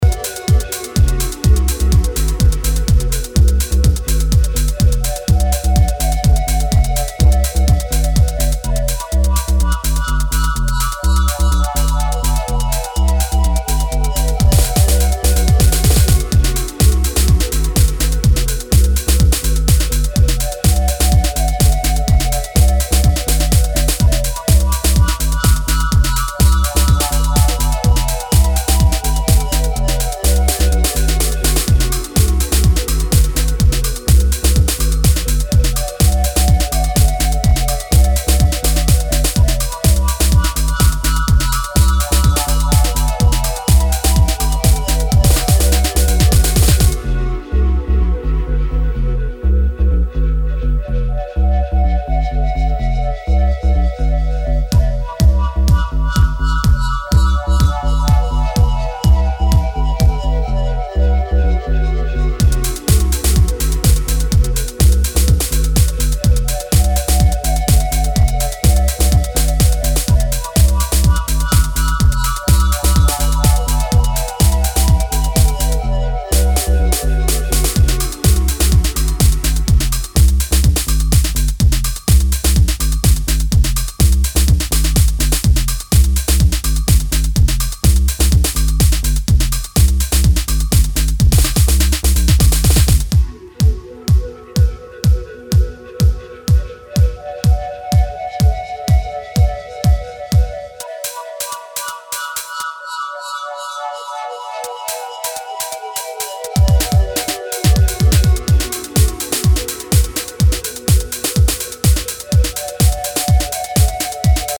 ranging from ghetto disco to heads down acid rollers